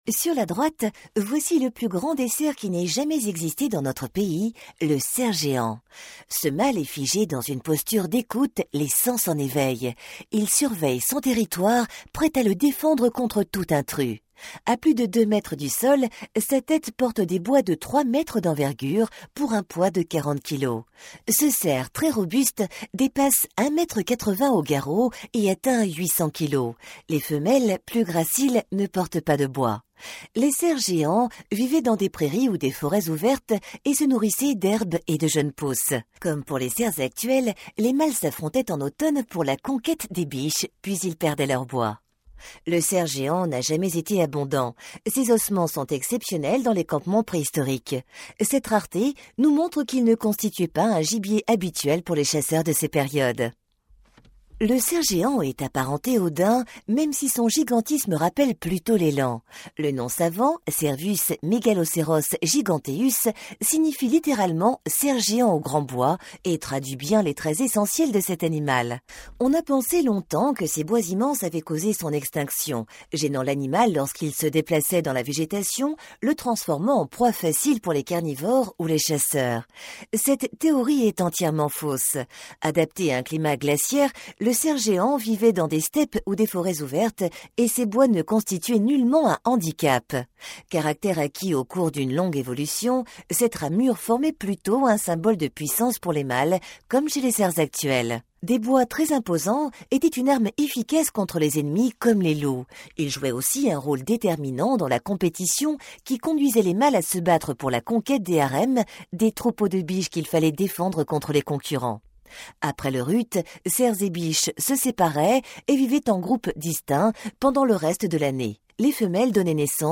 Voix de femme en français ⋆ Domino Studio
AUDIO-GUIDE